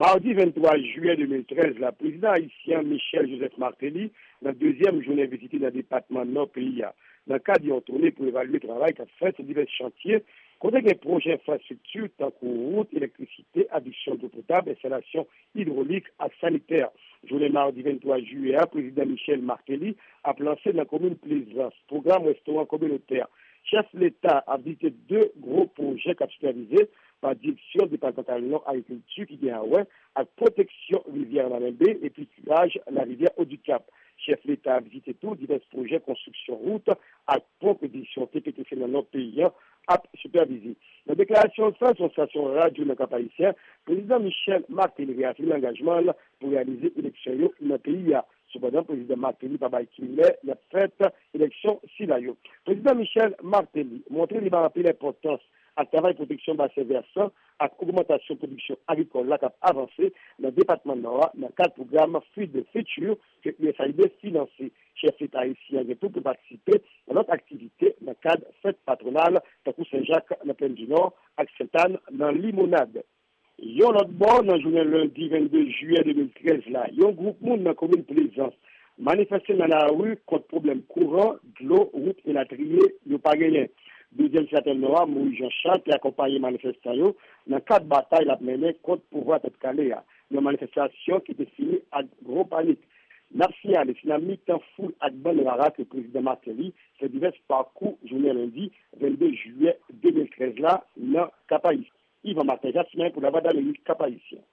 Pdt. Martelly nan Nò - Repòtaj